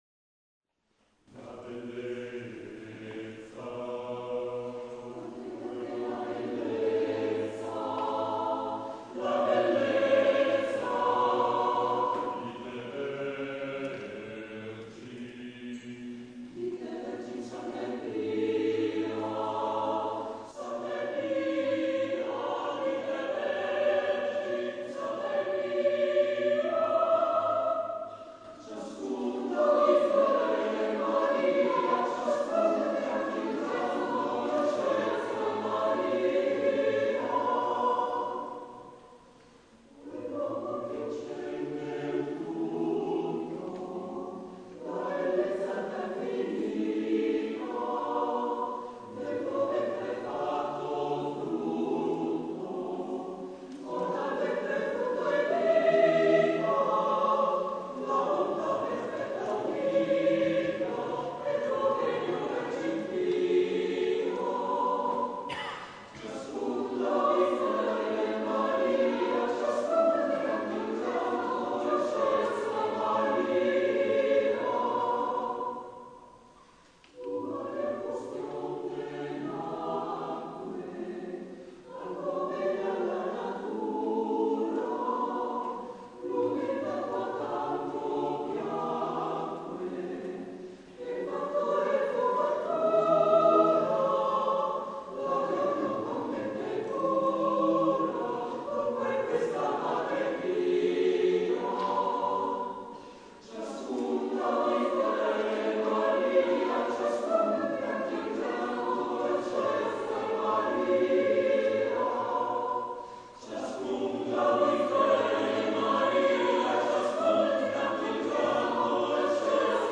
Number of voices: 4vv Voicing: SATB Genre: Sacred, Sacred song
Language: Italian Instruments: A cappella